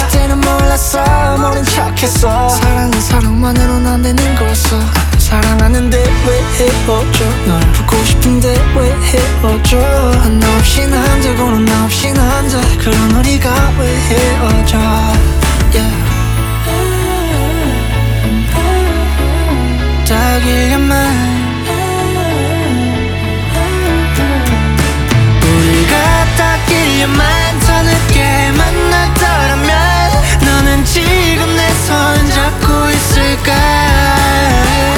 Жанр: Поп музыка / Рок
K-Pop, Pop, Rock